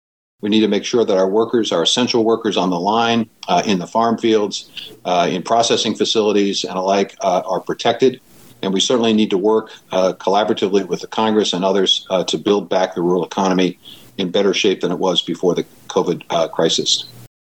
During his confirmation hearing, Ag Secretary Nominee Tom Vilsack says that Congress needs to work with the industry to build its strength back up better than before COVID.